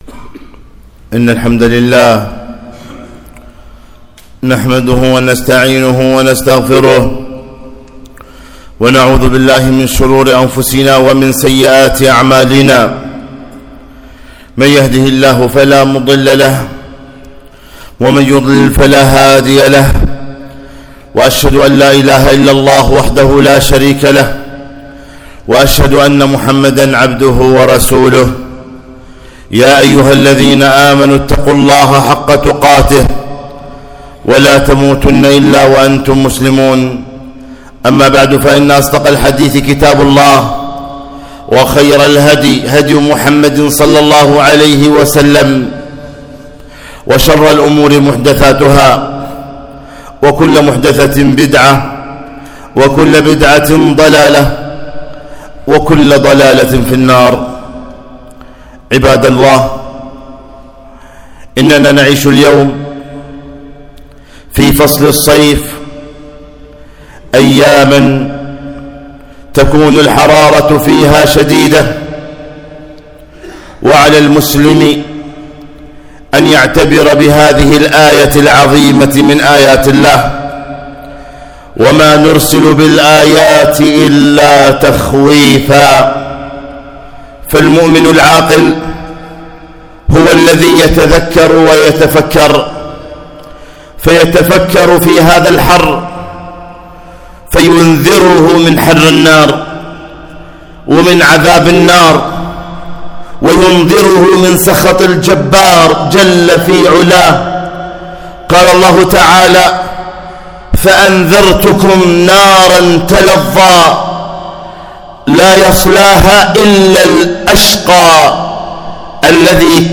خطبة - الاعتبار بحرارة الصيف